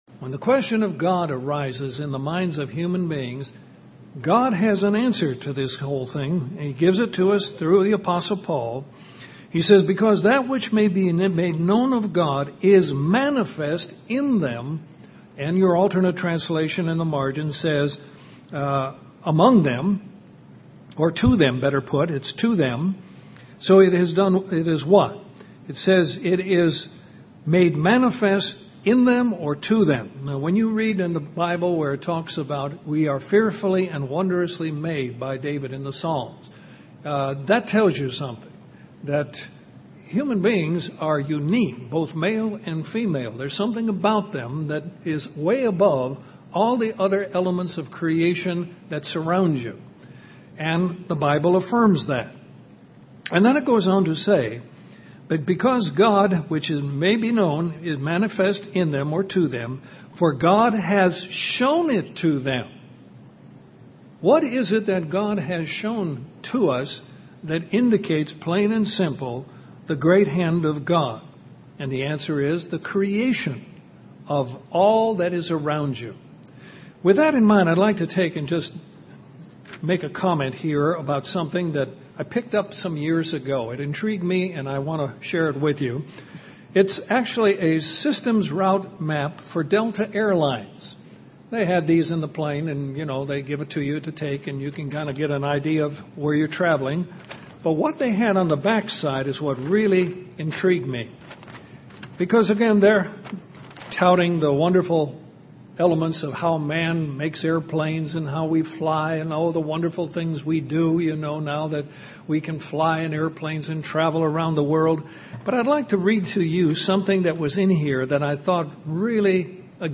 This sermon gives five points to help you understand the purpose for your life.